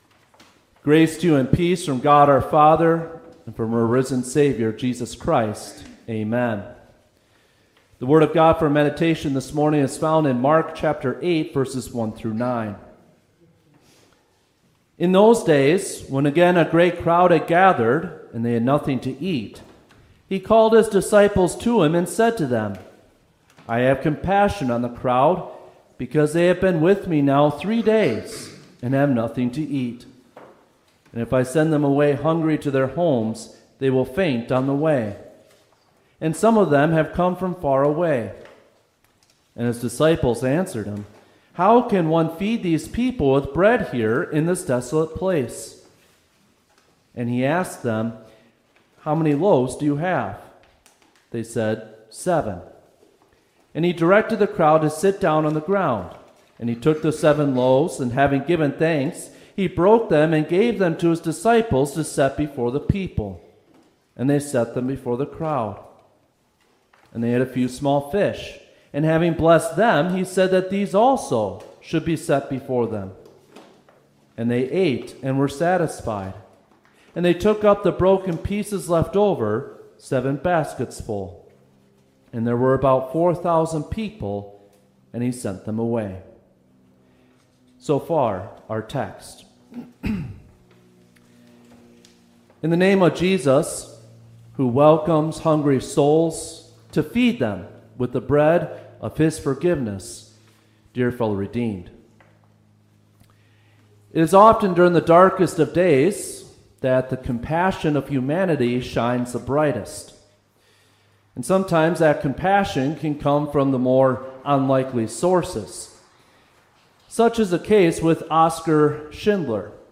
Seventh_Sunday_after_Trinity_Service_August_3_2025.mp3